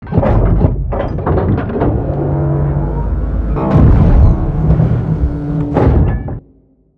tug.wav